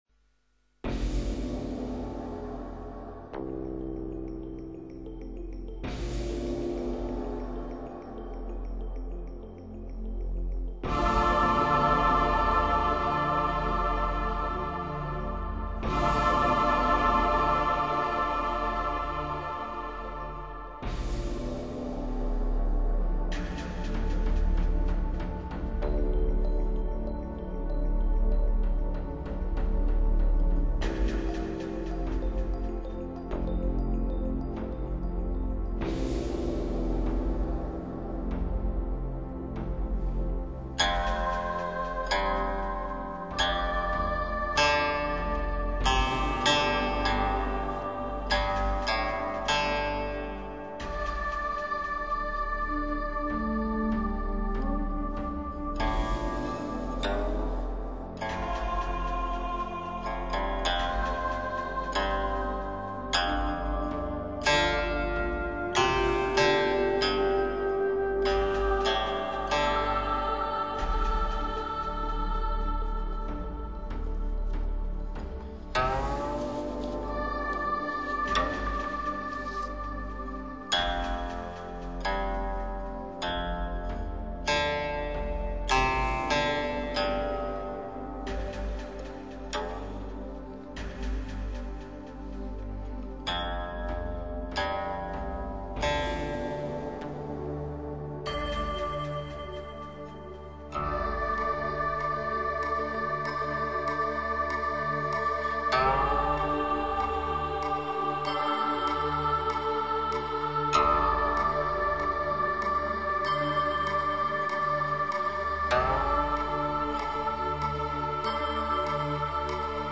[23/6/2009]一曲别样的《梅花三弄》在炎热的夏夜送给你一丝清凉与静谧